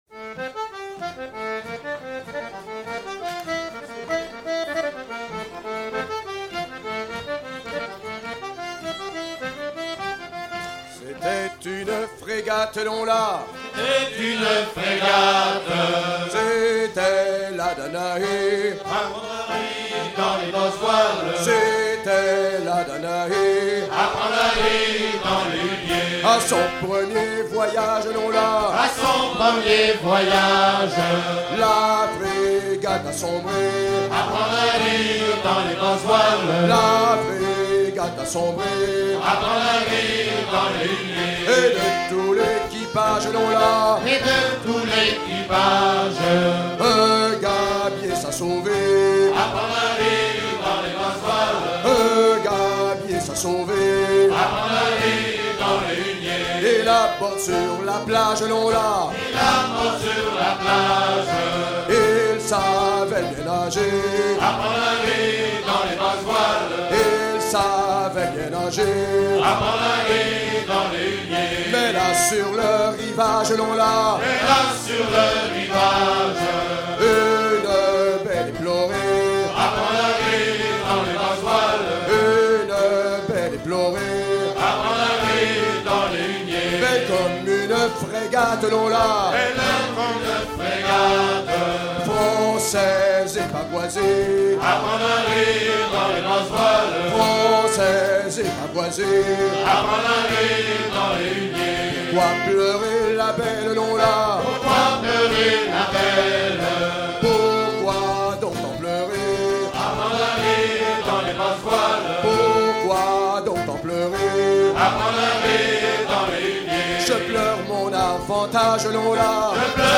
à virer au cabestan
Genre laisse
Pièce musicale éditée